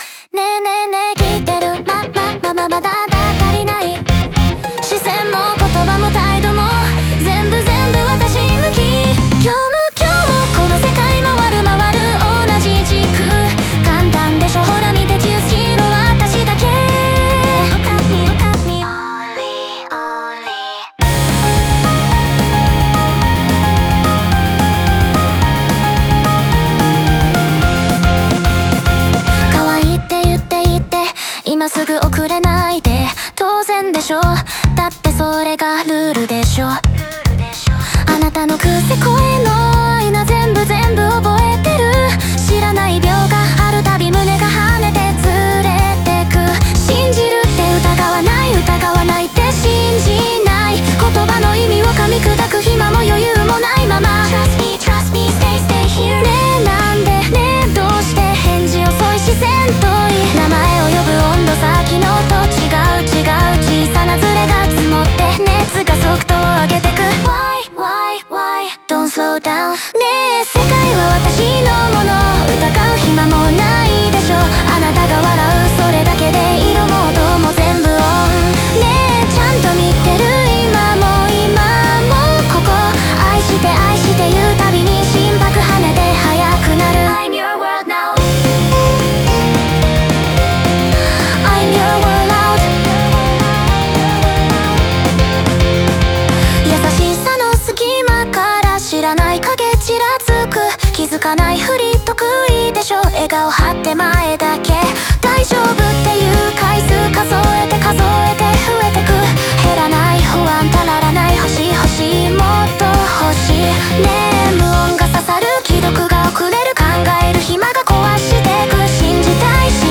オリジナル曲♪
この歌詞は、自分が愛の中心にいると信じ切っていた心が、加速する感情に飲み込まれていく過程を高速なリズムで描いています。
最後に訪れる急激な静寂は、速すぎた想いだけが取り残される余韻を残し、愛の未熟さと危うさを強く印象づけます。